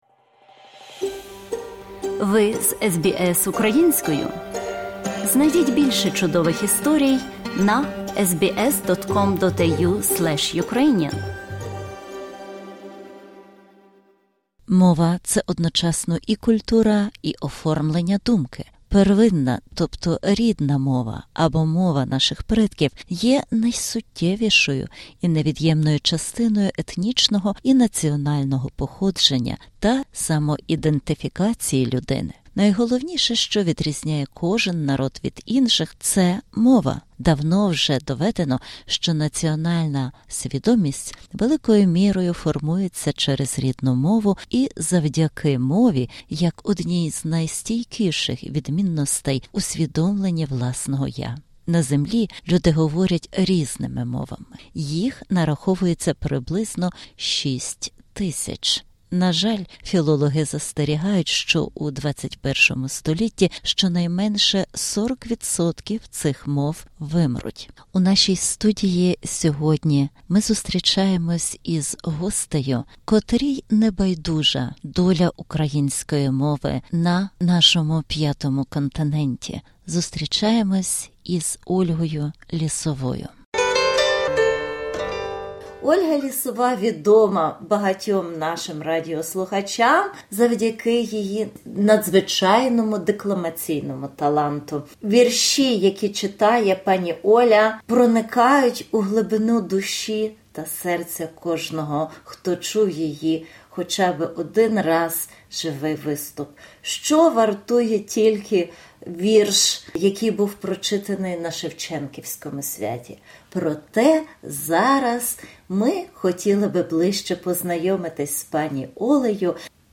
SBS Українською View Podcast Series